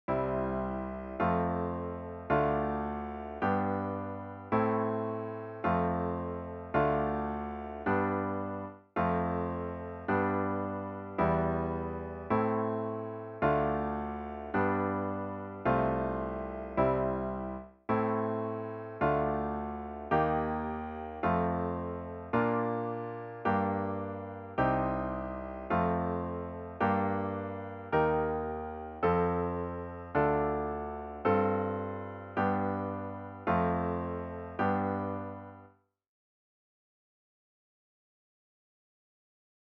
The examples are in C major/A minor, but are of course transposable to any key.
A new browser window will open, and you’ll hear each progression in basic long-tones.
Listen VERSE:  C  F  Dm  G  Am  F  Dm  G ||CHORUS:  F  G  Em  Am  Dm  G  F/C  C  || BRIDGE:  Am  Dm  Eb  F  Bb  Gm  Cm  F  Gm  Eb  F  Dm  F/G  G  F  G